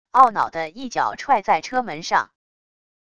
懊恼的一脚踹在车门上wav音频